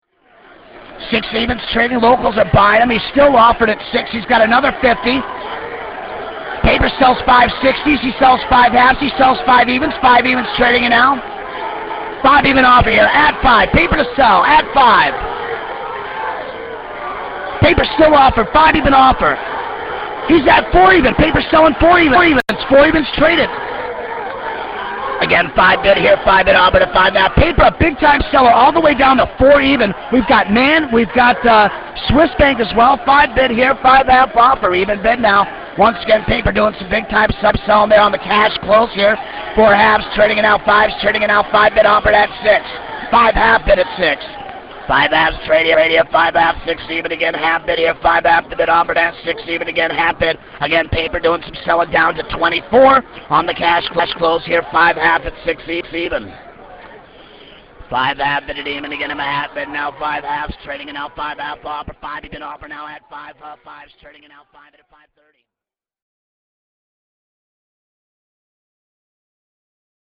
Traders Audio, one of the leading distributor of squawk box audio for traders, provides live coverage of all the pit action as it unfolds throughout the trading day.
Squawk box audio is the most unique and descriptive way to receive price and volume data and gain first hand knowledge of information that was once only available to floor traders. Their squawk commentator will make you feel as if you are standing right alongside him in the pit.